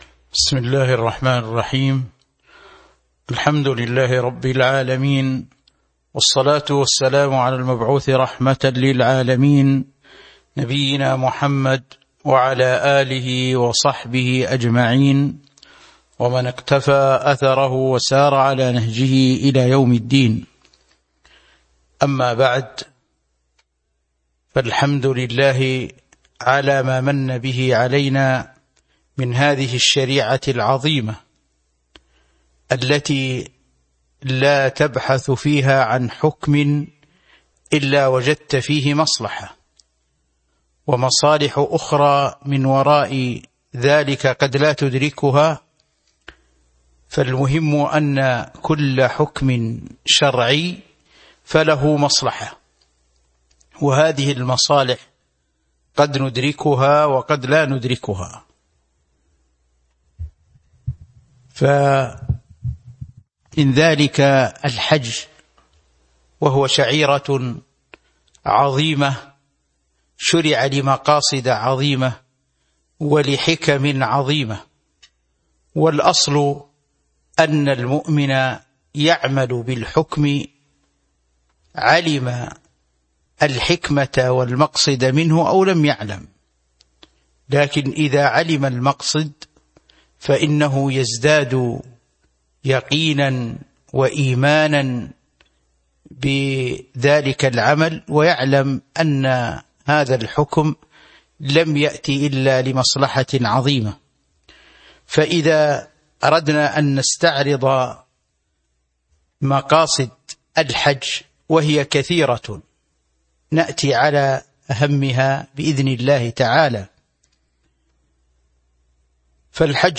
تاريخ النشر ٣ ذو الحجة ١٤٤٢ هـ المكان: المسجد النبوي الشيخ